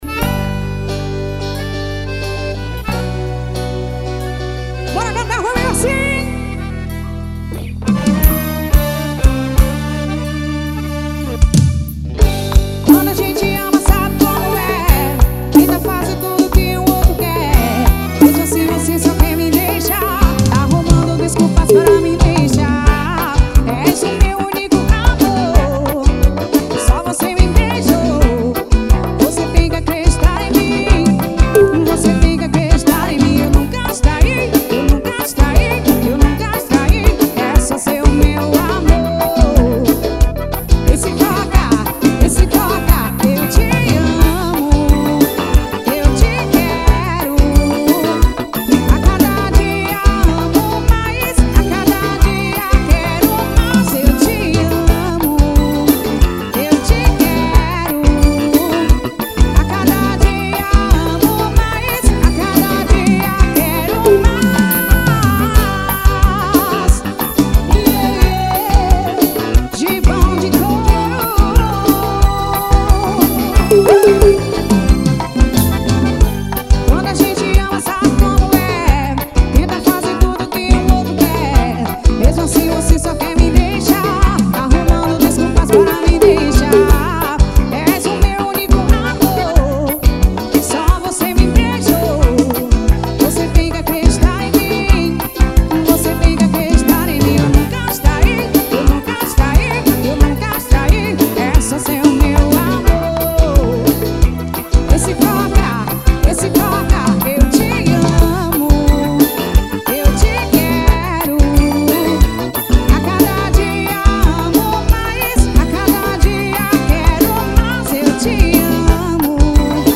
Ao Vivo em Capim Grosso BA.